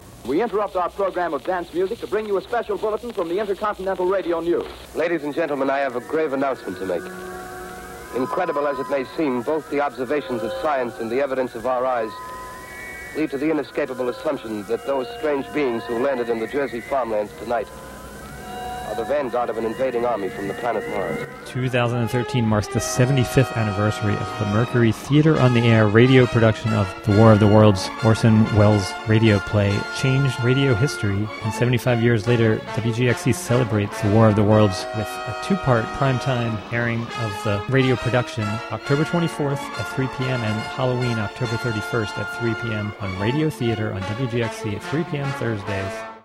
An Official promo spot for 75th anniversary broadcast of "The War of The Worlds." (Audio)